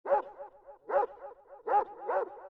dog2.mp3